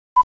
clock.ogg